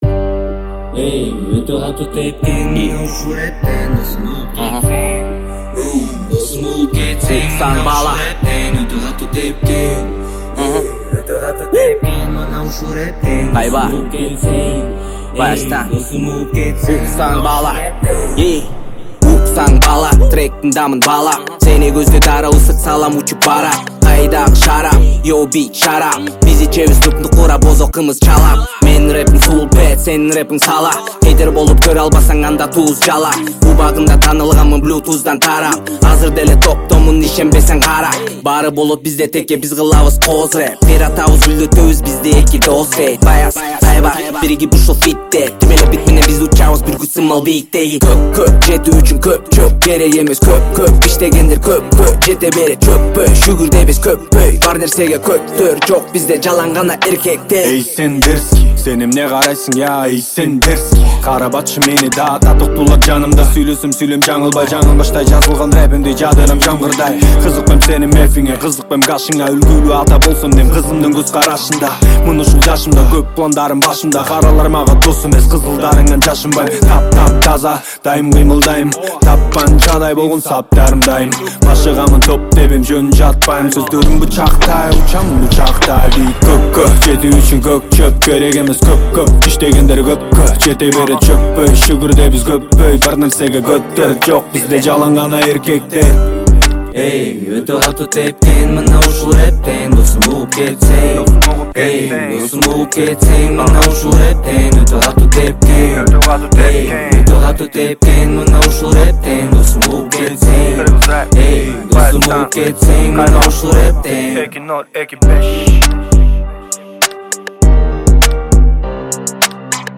• Категория: Кыргызские песни